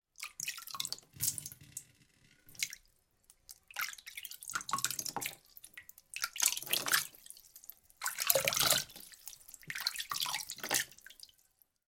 随机的 "水瓢滴水与手浴室的声音
描述：水瓢滴水与手浴室acoustic.flac
Tag: 浴室